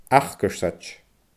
acarsaid /axgərsɪdʲ/